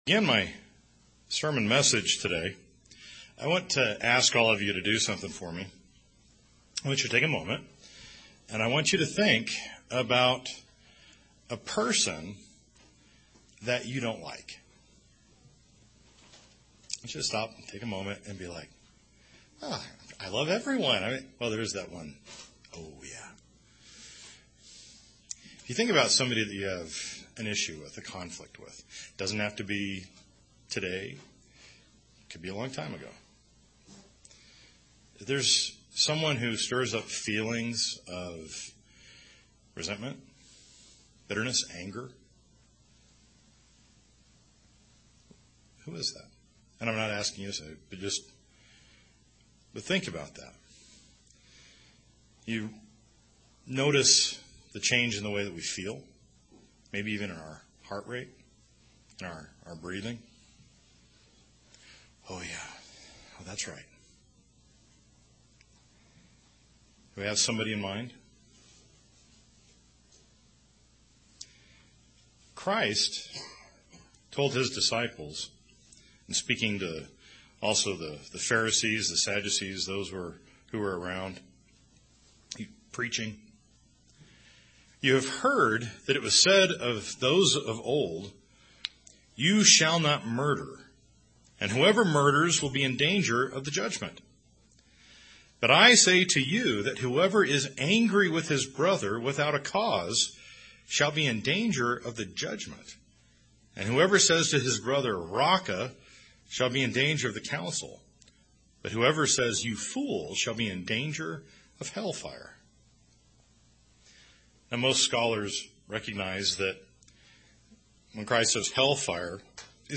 Sermons
Given in Denver, CO Loveland, CO